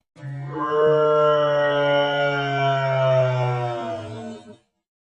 Звук глубокого замедления